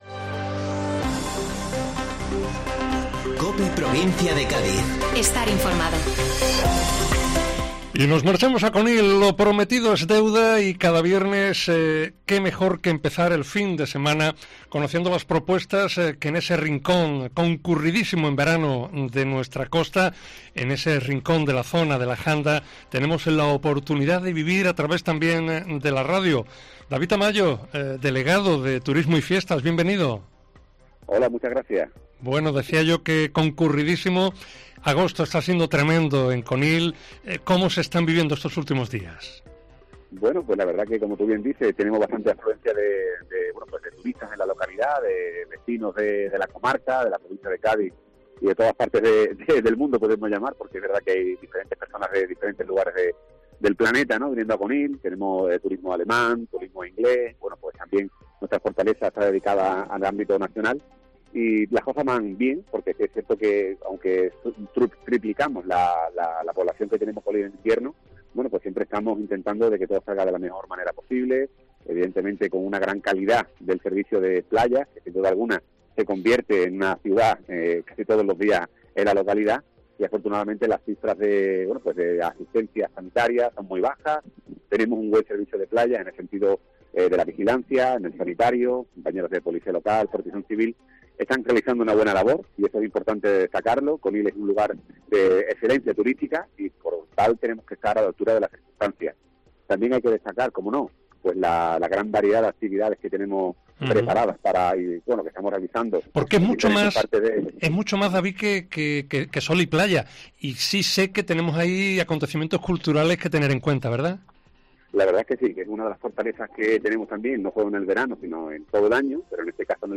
Escucha a David Tamayo que, además, se congratula de cómo marcha este verano 2022 en Conil.